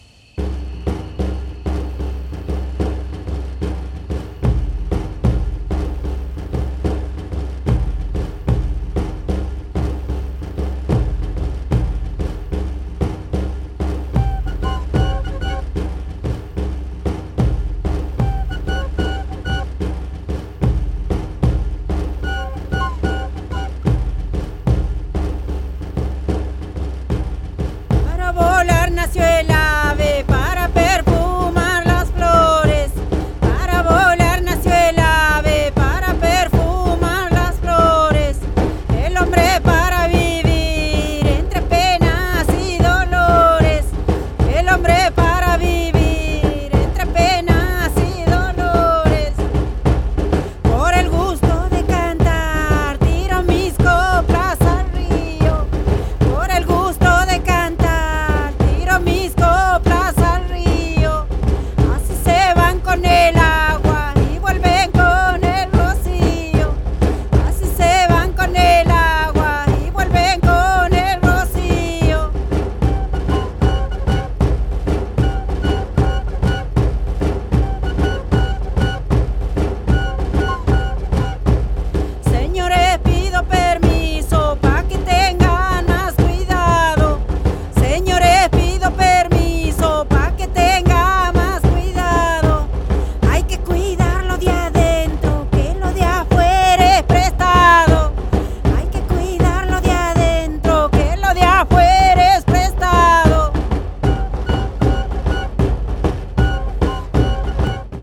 大傑作！！深遠な歌声とサイケデリックな音響デザインが絶妙に組合わさった最新型のフォルクローレ！！
音像はクールですがかなり沁みますよ！